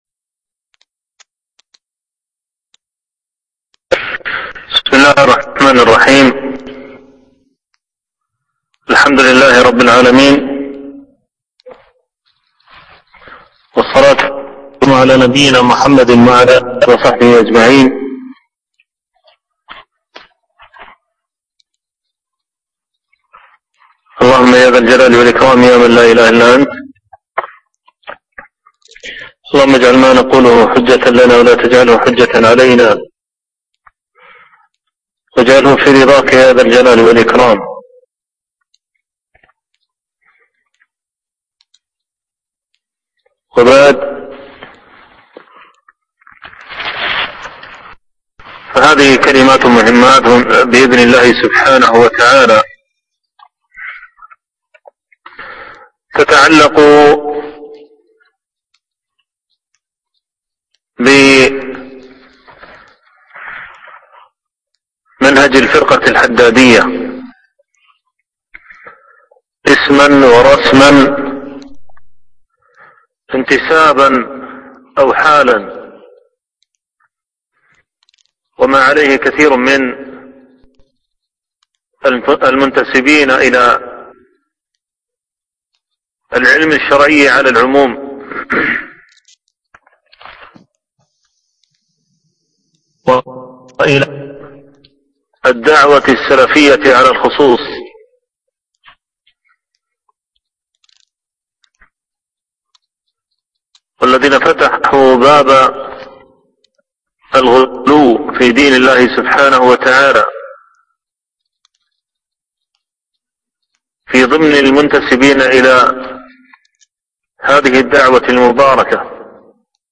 العنوان: الفروقات الجلية بين أهل السنة السلفيين وبين الحدادية الألبوم: محاضرات المدة